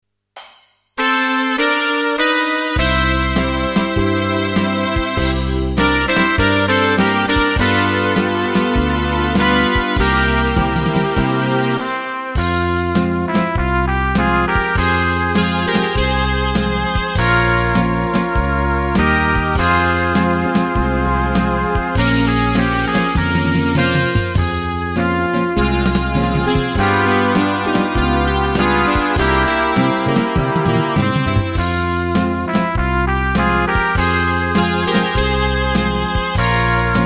Rubrika: Pop, rock, beat
- tango